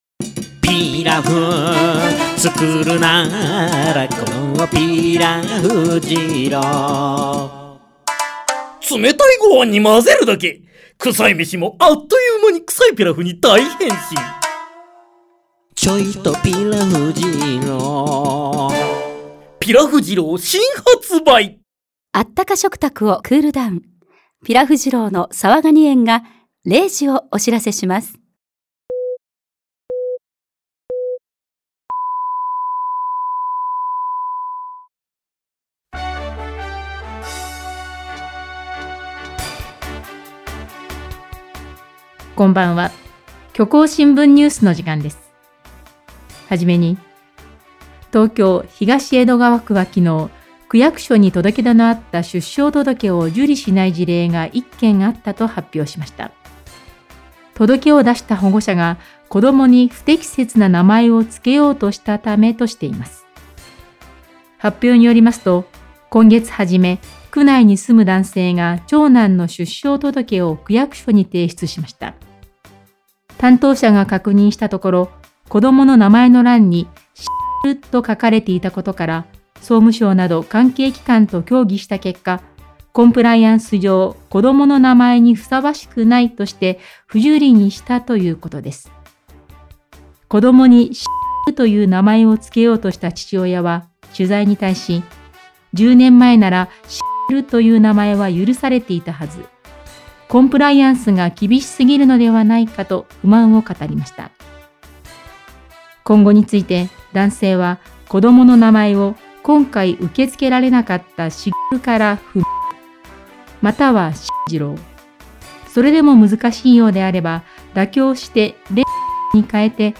「虚構新聞社」とインターネット放送局「プレイ」がお届けするニュース番組。世の中の様々なニュースをお届けしていきます。